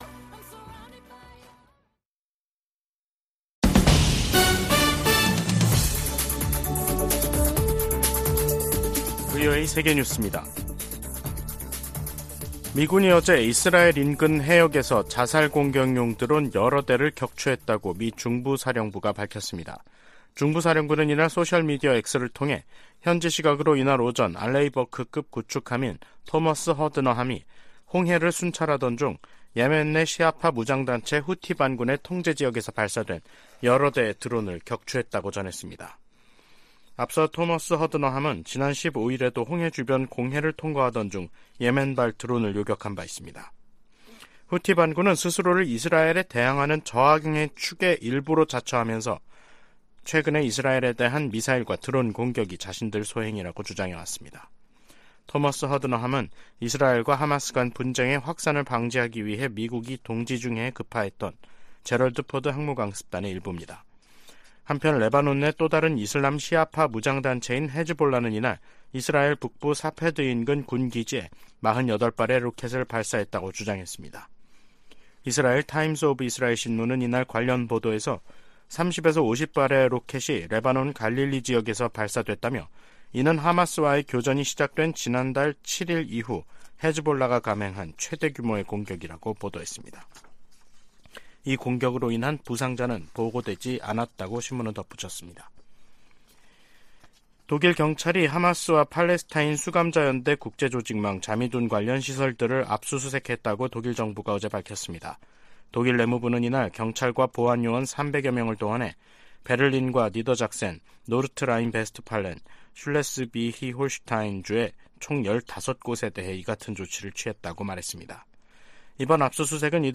VOA 한국어 간판 뉴스 프로그램 '뉴스 투데이', 2023년 11월 24일 3부 방송입니다. 미국과 유럽연합(EU)이 국제원자력기구 정기이사회에서 북한의 무기개발을 규탄했습니다. 북한이 쏴 올린 정찰위성 만리경 1호가 빠른 속도로 이동하면서 하루에 지구를 15바퀴 돌고 있는 것으로 확인됐습니다. 한국-영국 정부가 북한 해킹 공격의 위험성을 알리는 합동주의보를 발표했습니다.